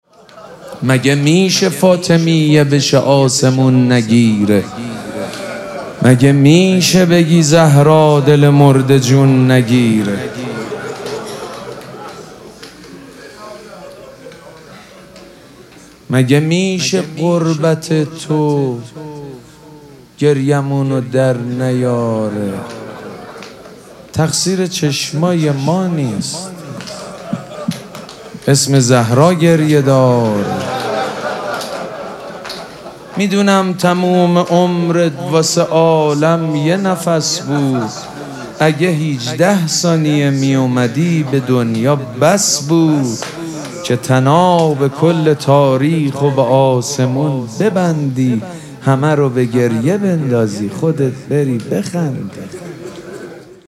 مگه میشه فاطمیه بشه آسمون نگیره - شعرخوانی شب پنجم مراسم فاطمیه 1403 - حاج سید مجید بنی فاطمه
دانلود فایل صوتی مداحی شعرخوانی روضه ای جدید فاطمیه 1403 سید مجید بنی فاطمه مگه میشه فاطمیه بشه آسمون نگیره در حسینیه ریحانه الحسین
شب پنجم فاطمیه 1403